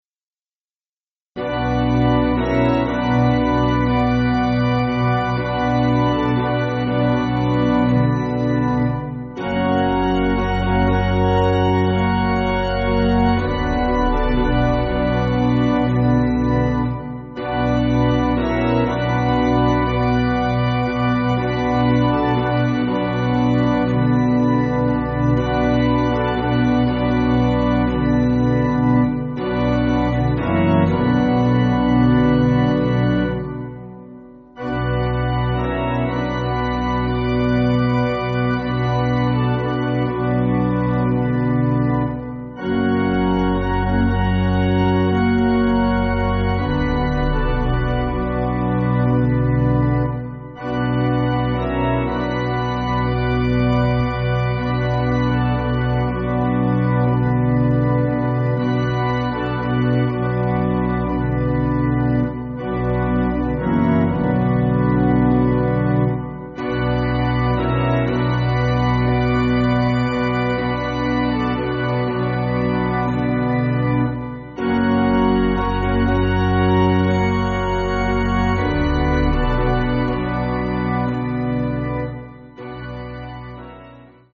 African-American Spiritual
Organ
(CM)   4/Bm
Soft Piano on Refrain with organ